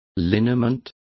Complete with pronunciation of the translation of liniment.